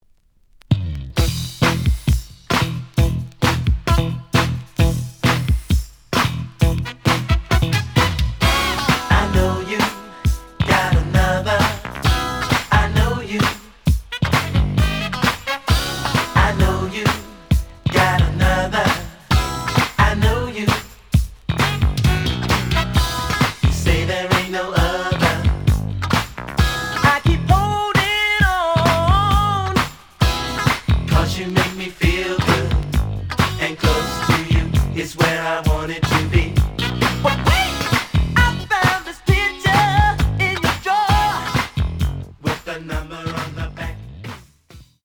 The audio sample is recorded from the actual item.
●Genre: Disco